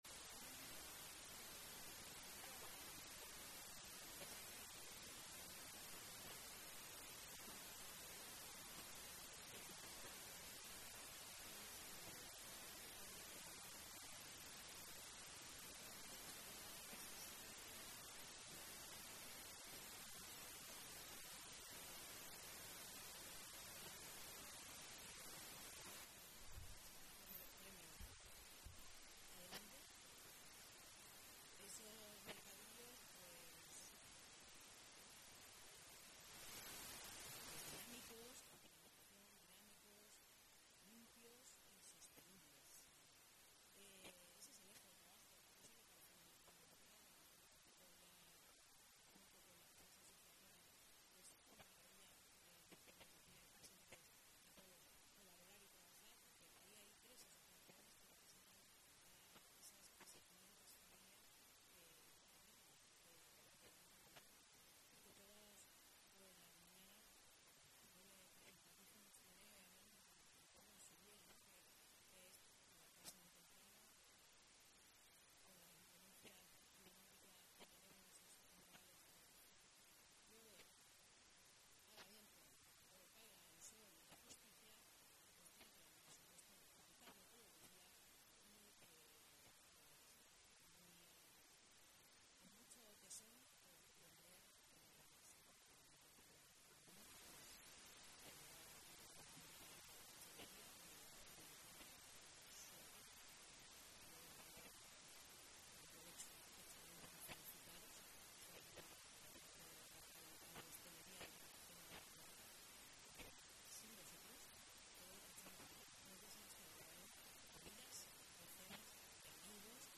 Los diez clientes ganadores del sorteo de regalos por comprar en los mercadillos de Cartagena durante las Navidades han recogido sus premios en el Palacio Consistorial este lunes, 20 de enero. Representantes de las asociaciones de vendedores ambulantes junto con la concejal de Comercio, Belén Romero, han entregado estos obsequios a los agraciados.